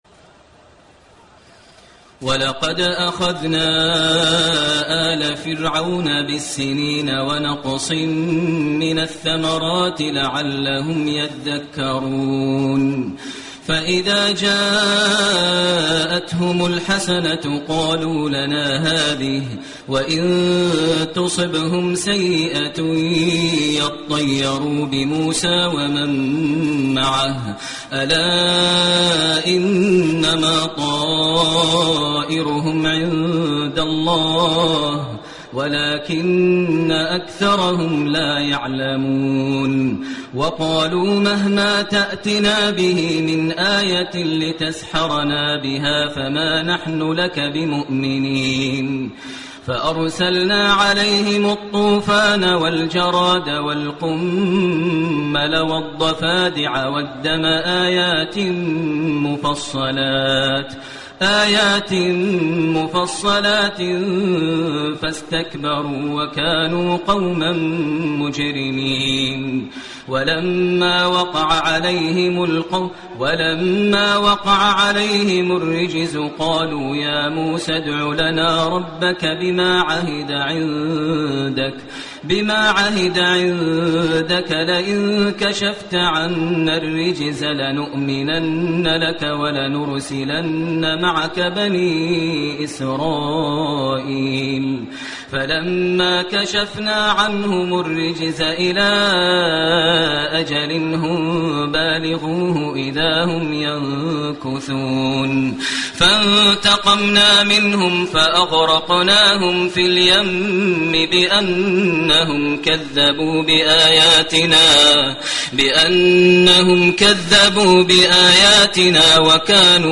تراويح الليلة التاسعة رمضان 1429هـ من سورة الأعراف (130-171) Taraweeh 9 st night Ramadan 1429H from Surah Al-A’raf > تراويح الحرم المكي عام 1429 🕋 > التراويح - تلاوات الحرمين